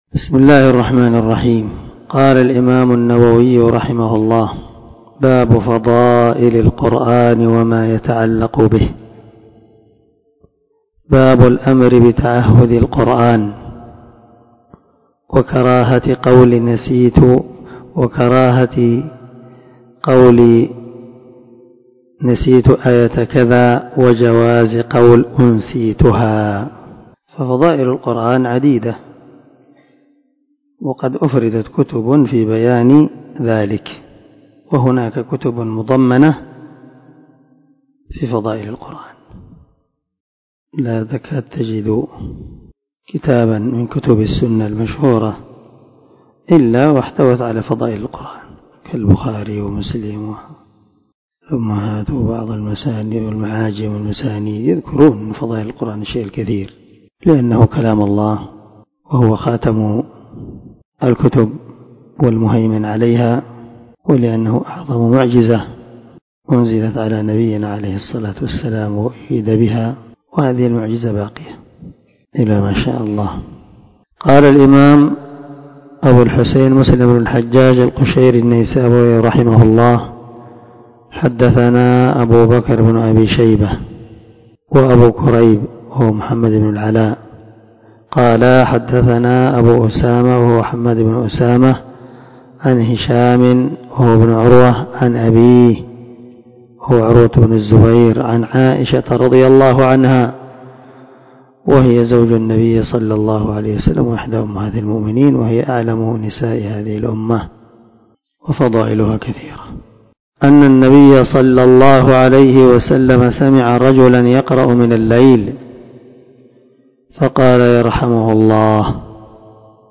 475الدرس 43 من شرح كتاب صلاة المسافر وقصرها حديث رقم ( 788 – 789 ) من صحيح مسلم
دار الحديث- المَحاوِلة- الصبيحة.